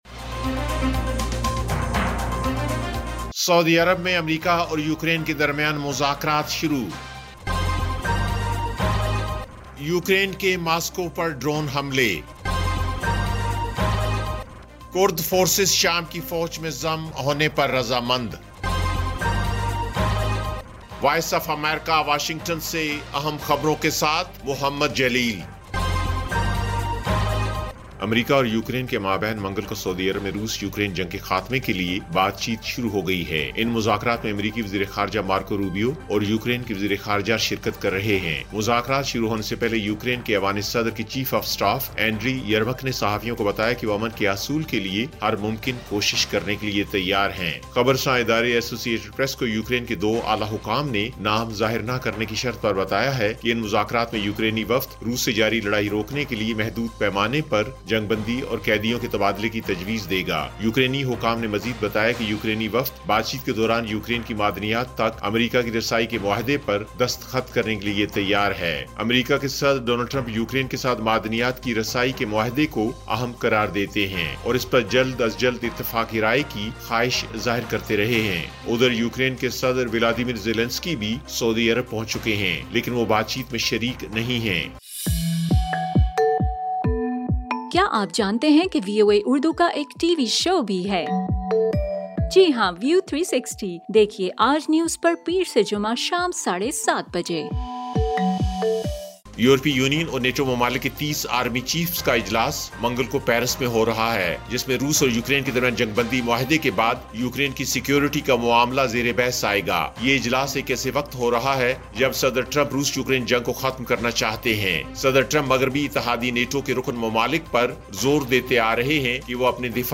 ایف ایم ریڈیو نیوز بلیٹن: شام 7 بجے